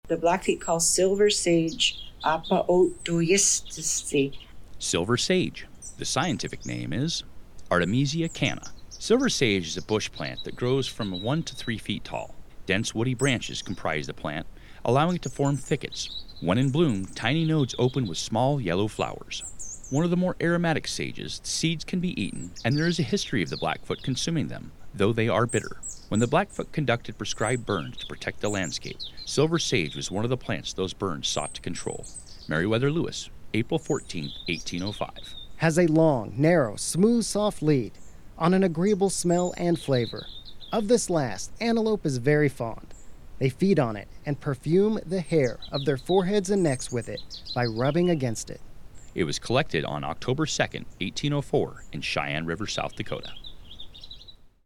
Narration: